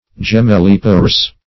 Search Result for " gemellipa-rous" : The Collaborative International Dictionary of English v.0.48: Gemellipa-rous \Gem`el*lip"a-rous\, a. [L. gemellipara, fem., gemellus twin + parere to bear, produce.] Producing twins.